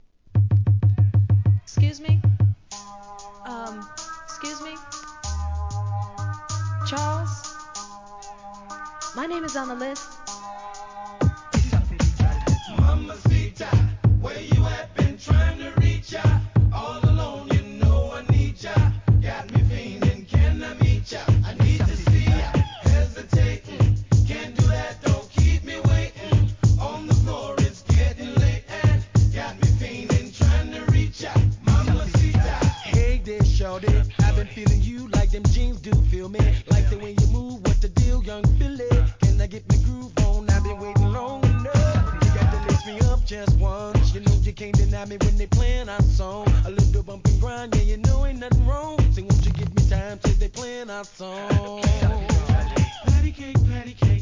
REGGAE, R&Bブレンド物!!